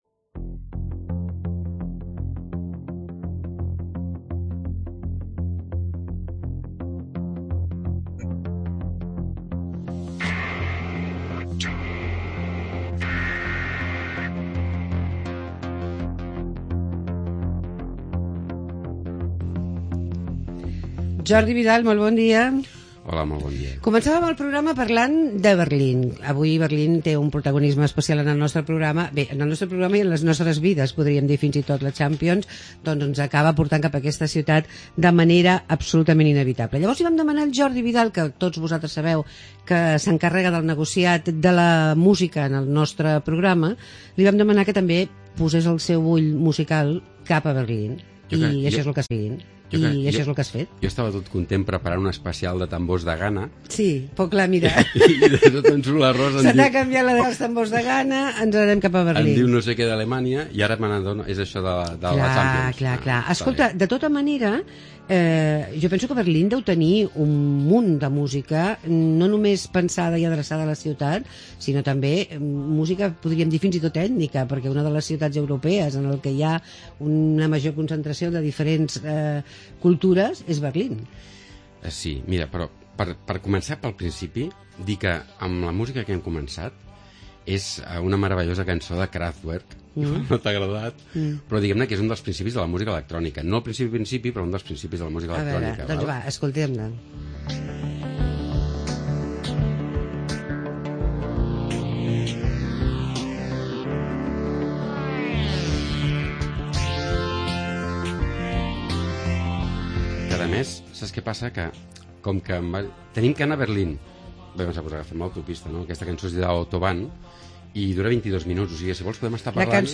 música alemana